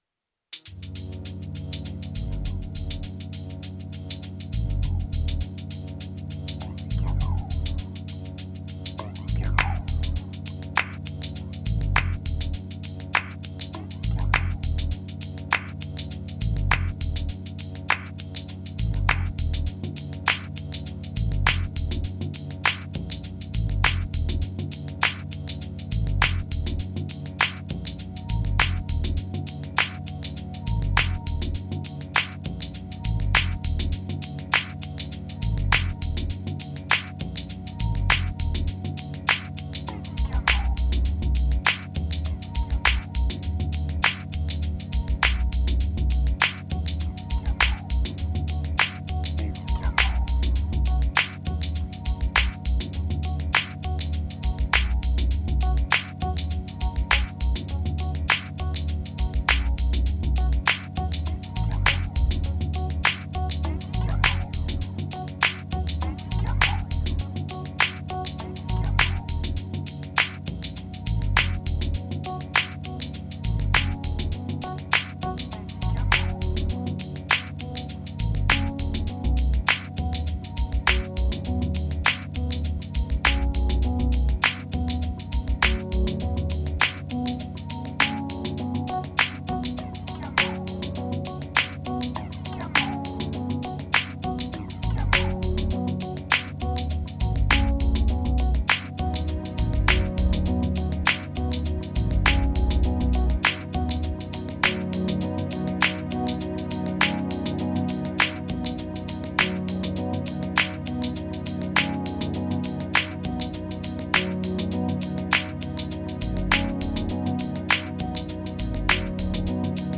Riddim Peace Triphop Chillout Groove Explorer Relief